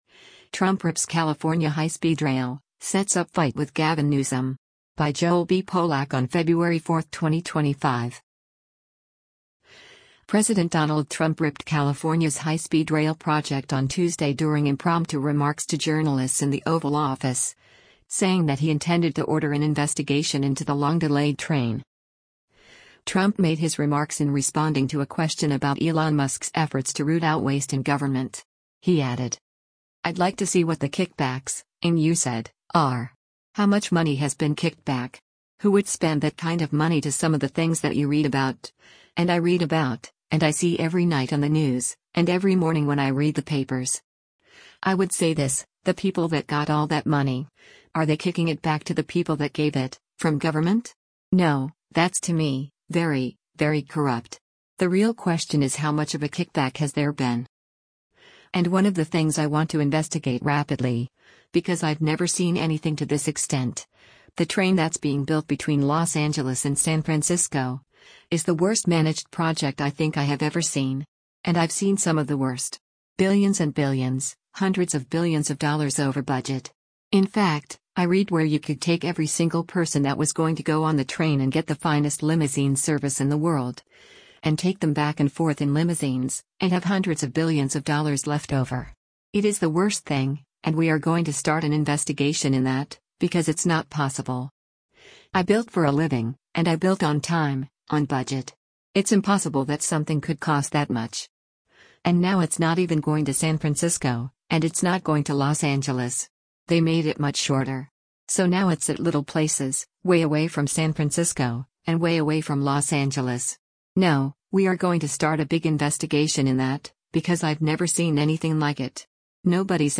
President Donald Trump ripped California’s high-speed rail project on Tuesday during impromptu remarks to journalists in the Oval Office, saying that he intended to order an investigation into the long-delayed train.